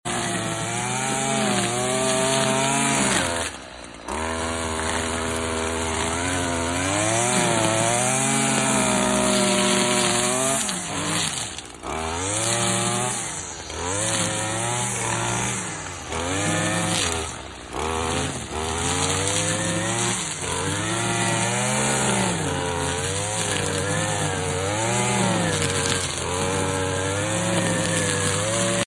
Trimmer Sthil Fs 55 R Sound Effects Free Download